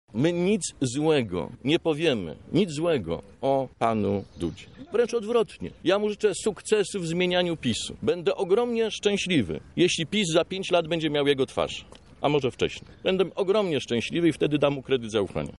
Konferencja PO